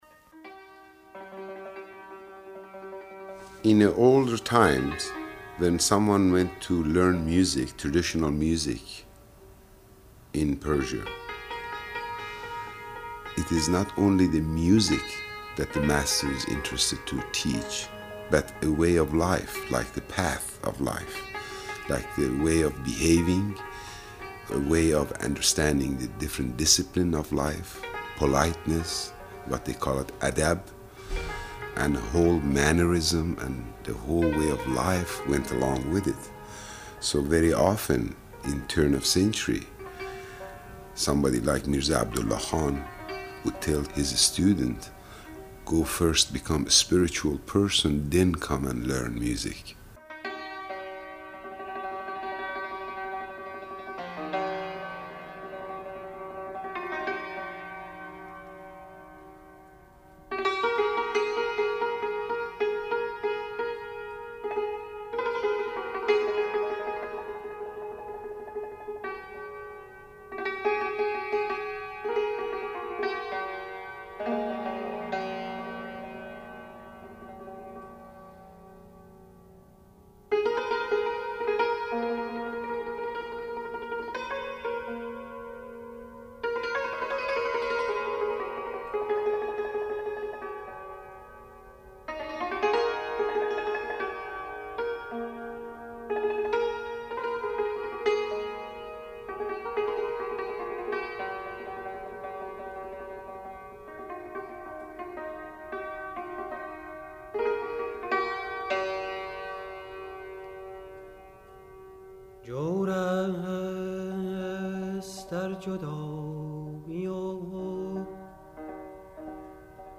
PERSIAN
Persian sufi music in NYC; spike fiddle in LA
Persian.mp3